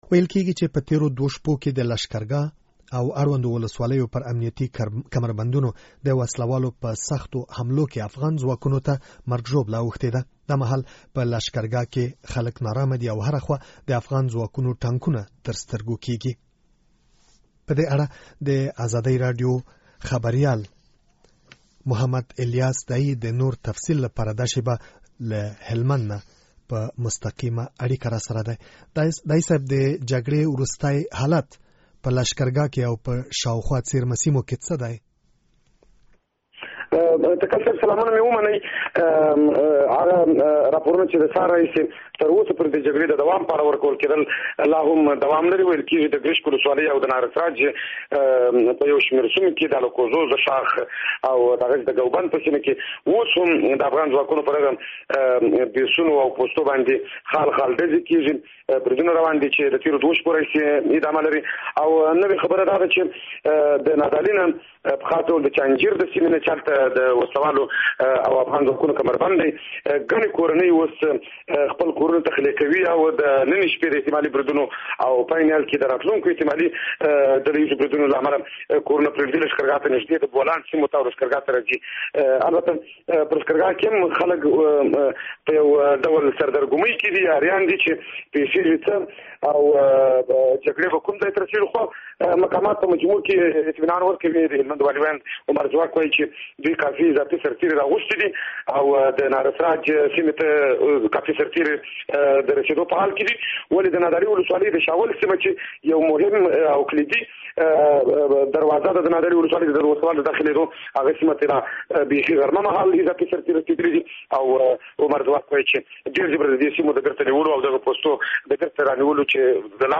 د هلمند راپور